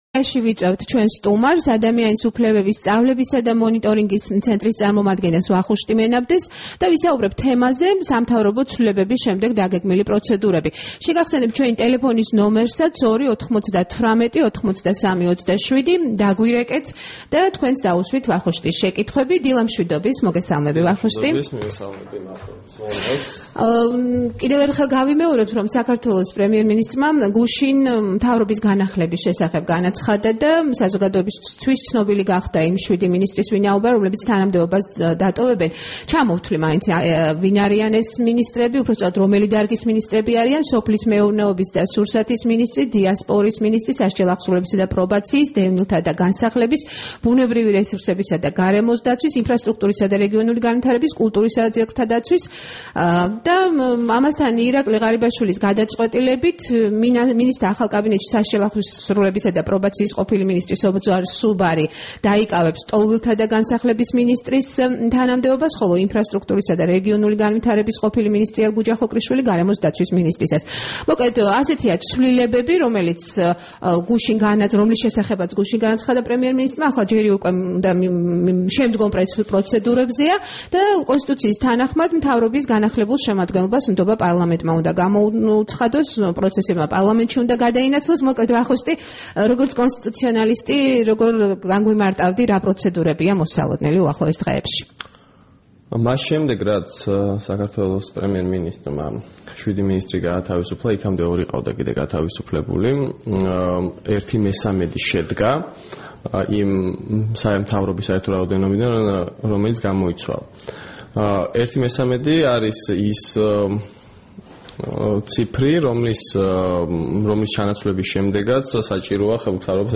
სტუმრად ჩვენს ეთერში
საუბარი